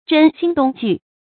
甄心动惧 zhēn xīn dòng jù
甄心动惧发音
成语注音ㄓㄣ ㄒㄧㄣ ㄉㄨㄙˋ ㄐㄨˋ